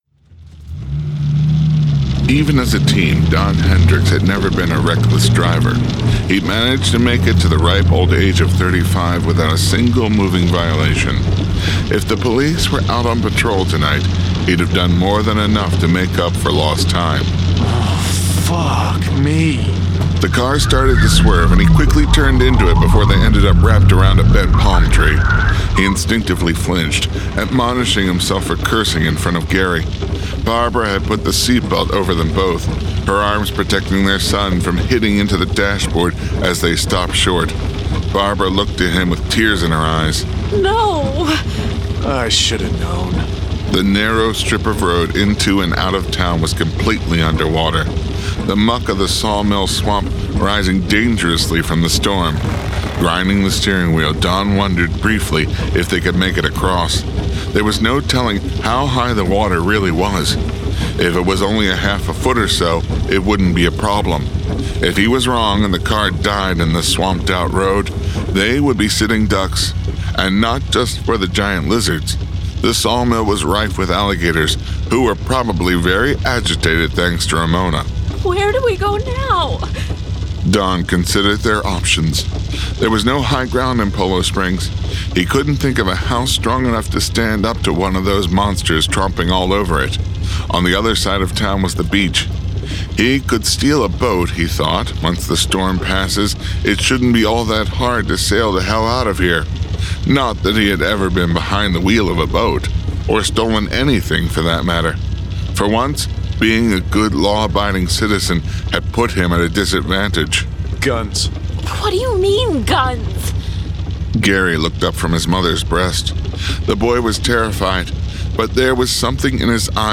One Size Eats All: Rattus New Yorkus Jurassic Florida and The Devil's Fingers [Dramatized Adaptation]
Genre: Horror
Sound Designers